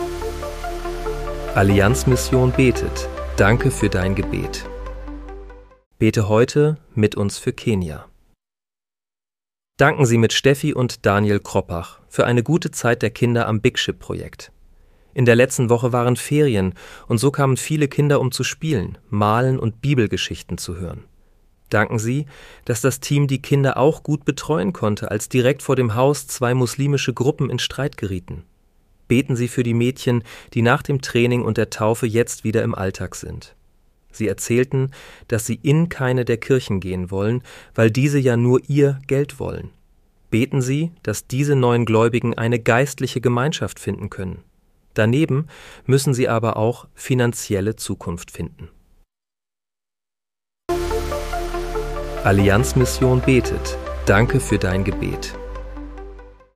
Bete am 09. März 2026 mit uns für Kenia. (KI-generiert mit der